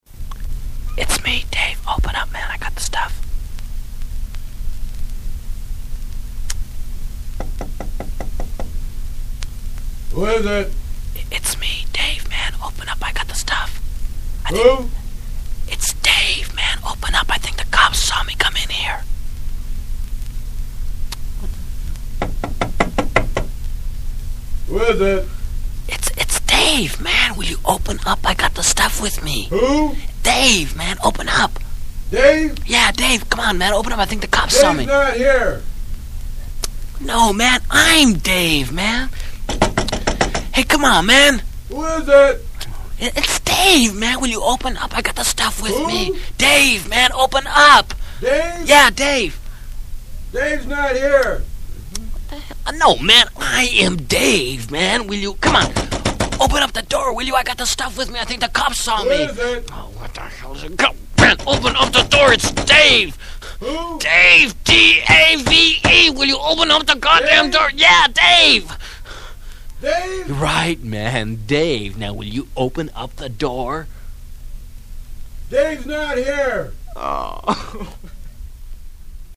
Das kultige US-Comedy-Duo Cheech & Chong mit seinem Sketch 'Dave' und dem legendären 'Santa Clause'.